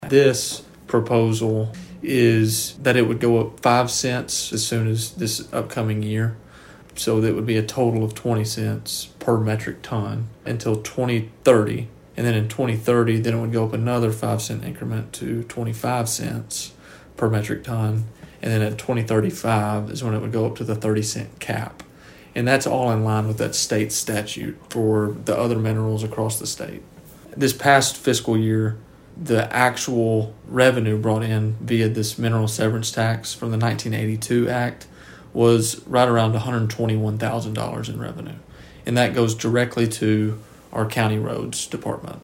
mayor-1.mp3